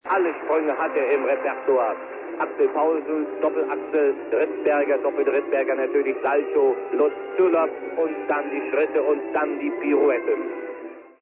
Sie sollen streiflichtartig die Wortmächtigkeit und sprachliche Kreativität des Reporters zeigen.
Olympische Spiele 1964 in Innsbruck: Eiskunstlauf des Münchner Champions Manfred Schnelldorfer